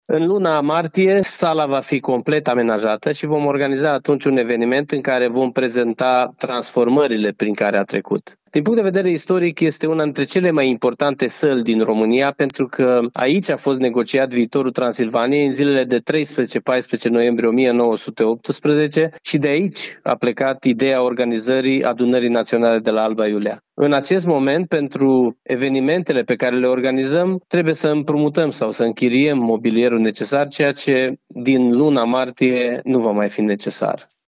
Sala va dispune de 100 de locuri în fotolii, de prezidiu și mese pentru şedinţe. De asemenea, este dotată cu instalaţie de sonorizare, sistem de numărare electronică a voturilor, sistem de traducere şi un ecran LCD, spune președintele Consiliului Județean Arad, Iustin Cionca.